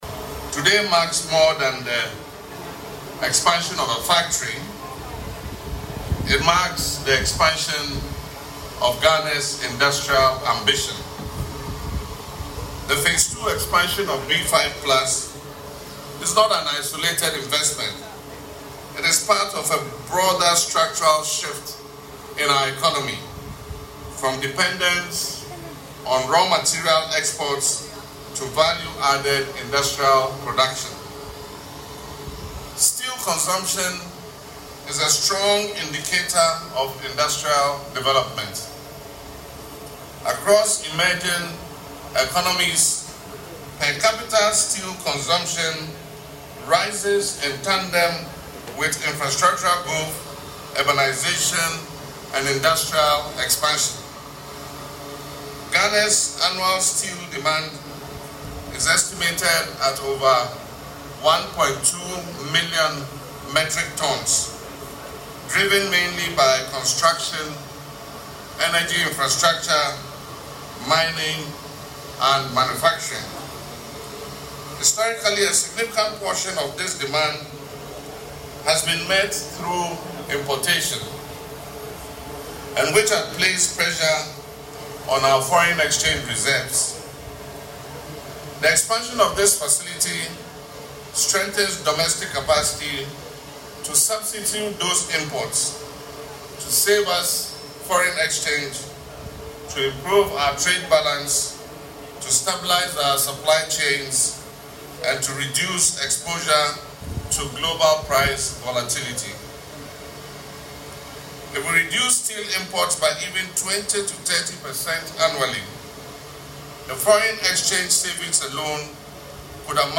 Speaking at the commissioning ceremony on Friday, February 20, President Mahama described the Phase Two expansion of the factory as a significant milestone in Ghana’s economic restructuring efforts, emphasising the country’s transition toward value-added industrial production.
LISTEN TO PRESIDENT MAHAMA IN THE AUDIO BELOW: